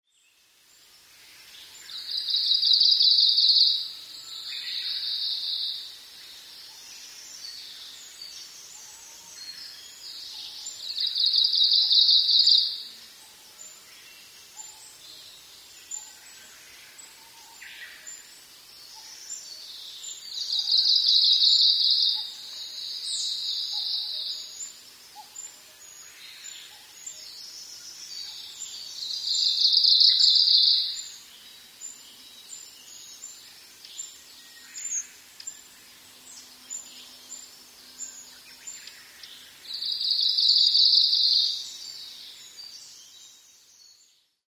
mebosomushikui_s1.mp3